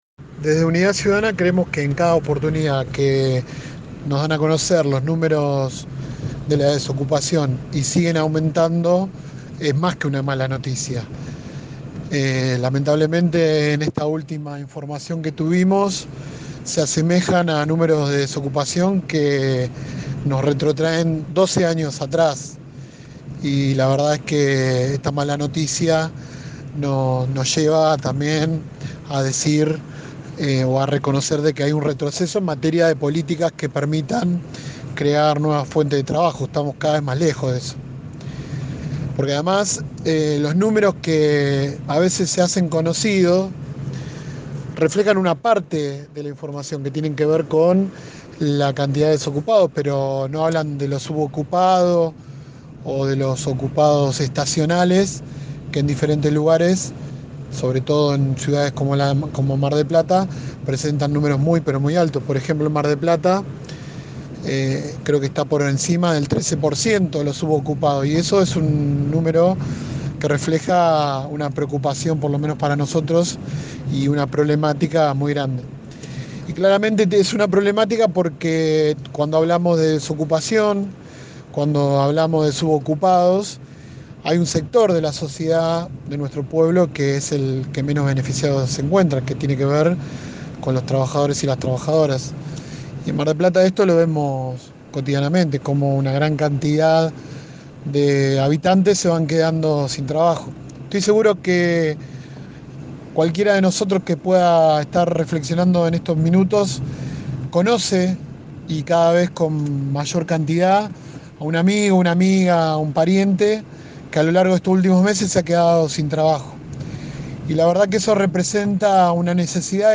Marcos Gutiérrez, concejal de Unidad Ciudadana, dialogó en el programa radial Bien Despiertos, que se emite de lunes a viernes de 7 a 9 por 104.3 sobre la publicación generada por el Indec y los nuevos índices de desocupación que nos llevaron al puesto número 10.